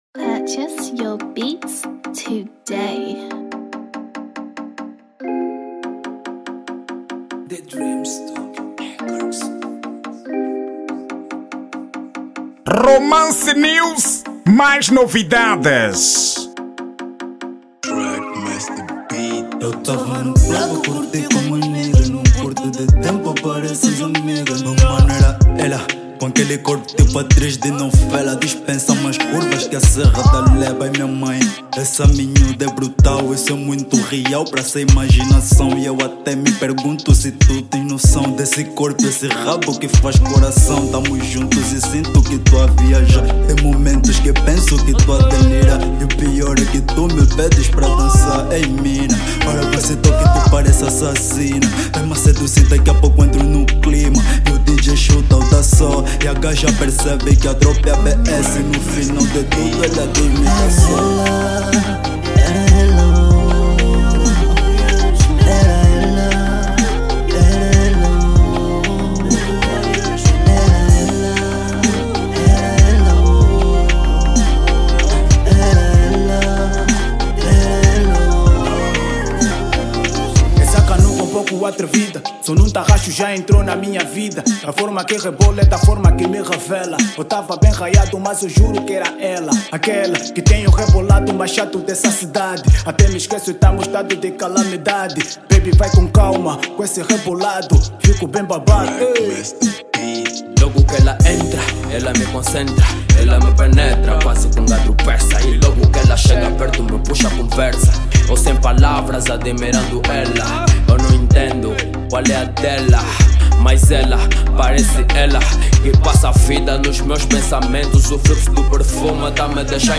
Estilo: Rap Love